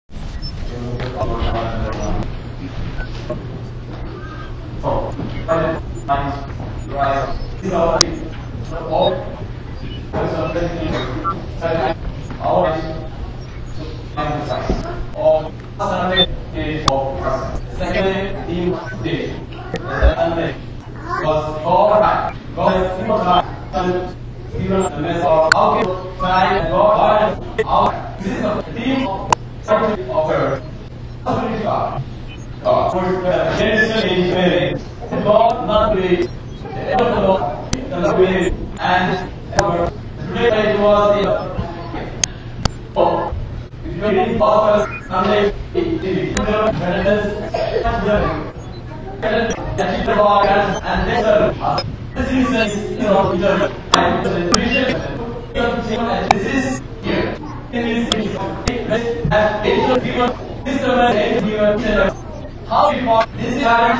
Sunday Sermon on 2010-Dec-26 by H.G.Alexios Mor Eusebius (Diocesan Metropolitan)